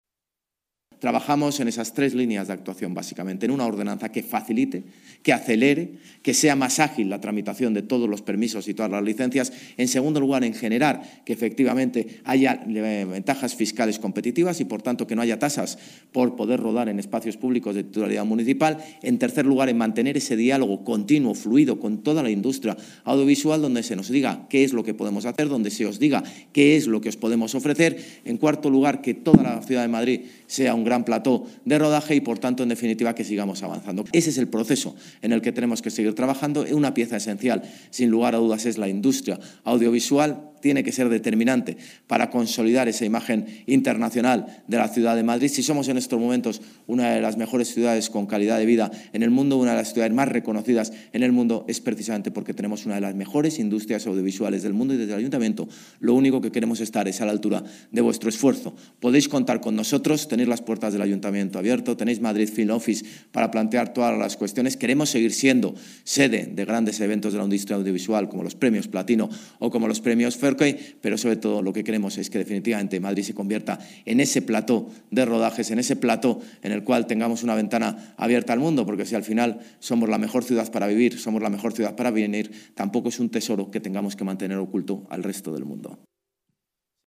En un encuentro con el sector organizado por el Ayuntamiento, en el que se han dado cita representantes de todos los segmentos de esta industria
Nueva ventana:José Luis Martínez-Almeida, alcalde de Madrid